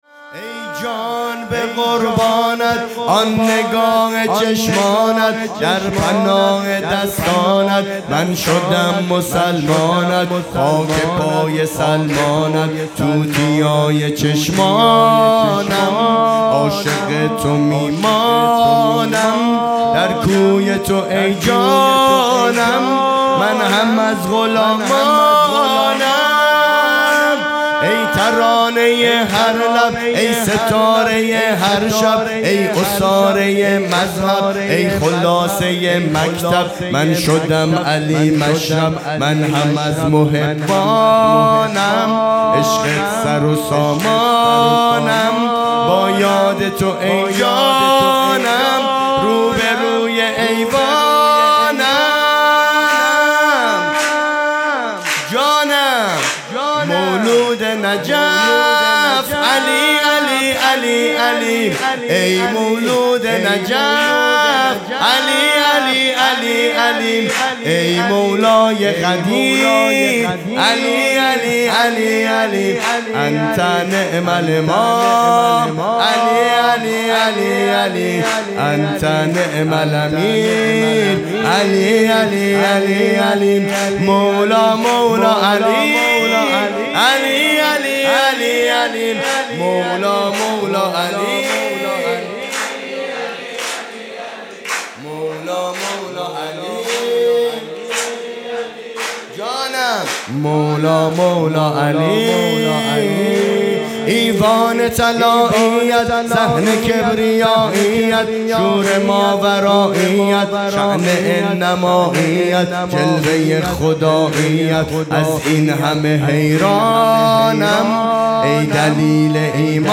خیمه گاه - هیئت بچه های فاطمه (س) - سرود | ای جان به قربانت، آن نگاه چشمانت | یک شنبه ۱۵ اسفند ۱۴۰۰